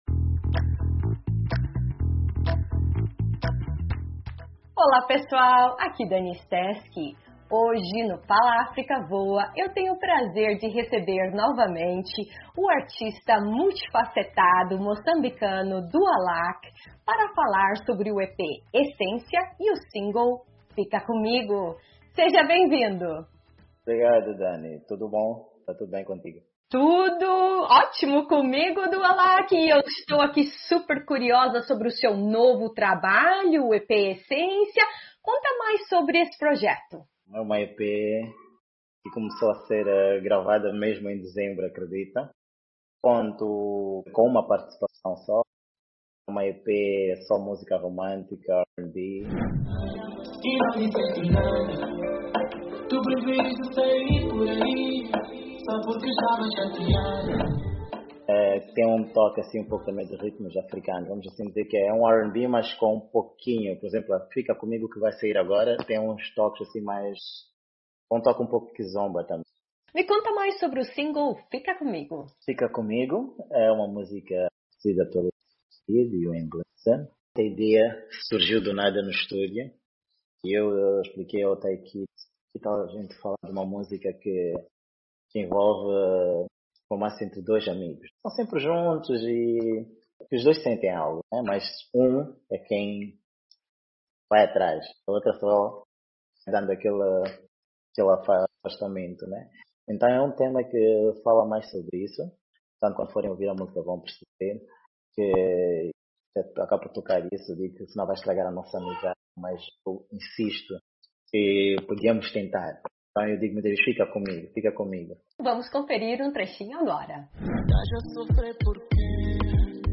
A canção retrata a história de dois amigos, em que um deles deseja iniciar um relacionamento amoroso, enquanto o outro tem receio de arriscar e perder a amizade. O single traz uma fusão de R&B com toques de kizomba.